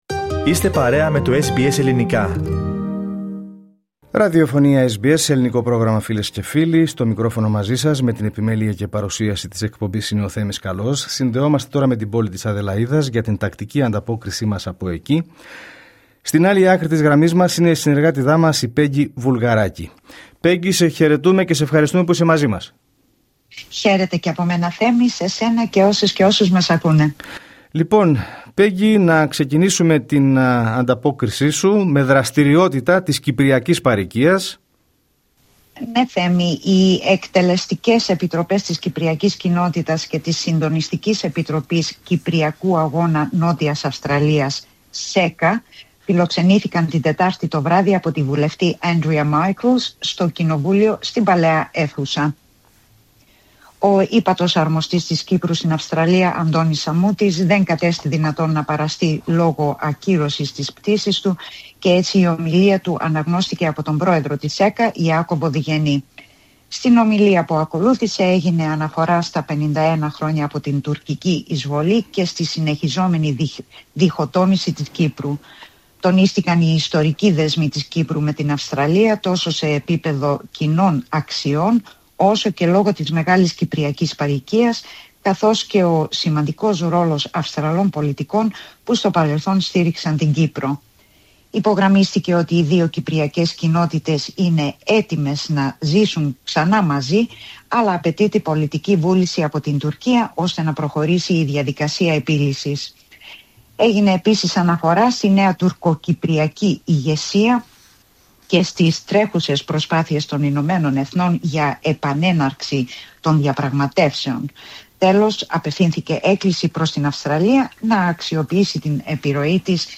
Ανταπόκριση-Αδελαΐδα: Η Κυπριακή παροικία Νότιας Αυστραλίας τιμά τα 51 χρόνια από την εισβολή
Ακούστε αναλυτικά την ανταπόκριση από την Αδελαΐδα πατώντας PLAY δίπλα από την κεντρική εικόνα.